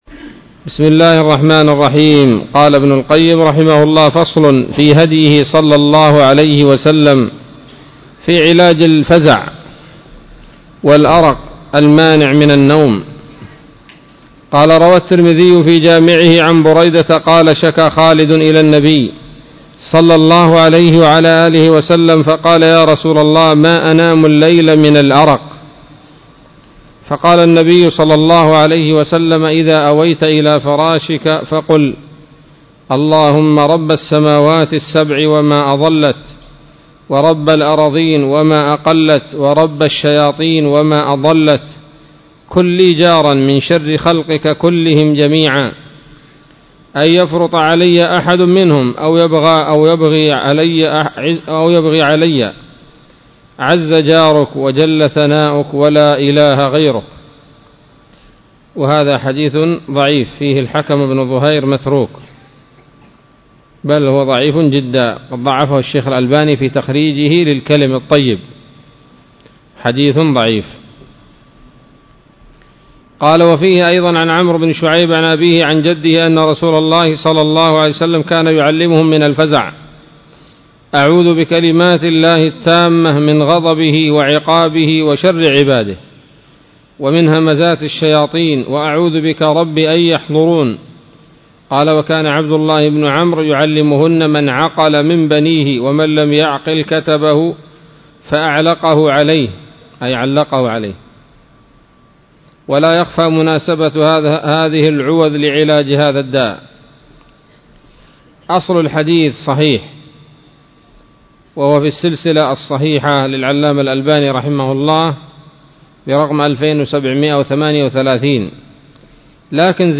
الدرس الثامن والخمسون من كتاب الطب النبوي لابن القيم